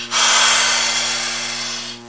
c_viper_yes.wav